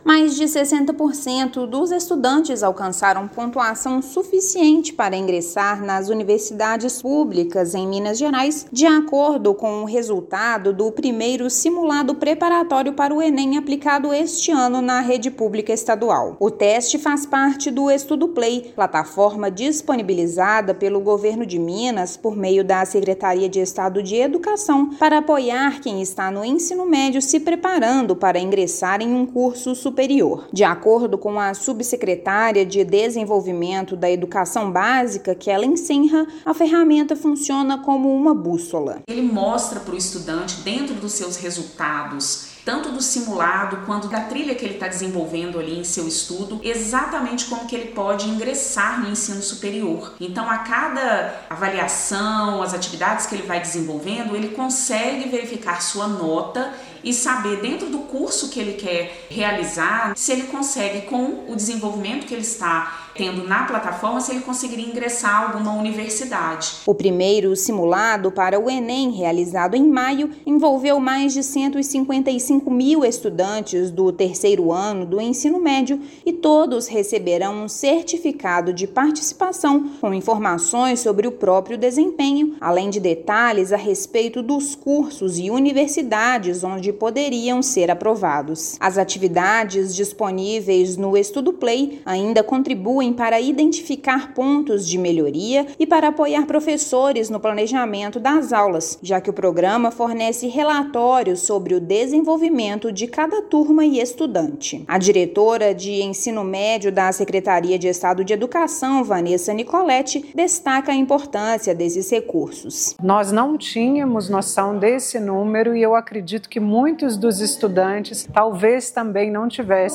Ferramenta da plataforma Enem MG, do Governo de Minas, avalia chances de aprovação em diversos cursos e universidades com base nas notas dos estudantes. Ouça matéria de rádio.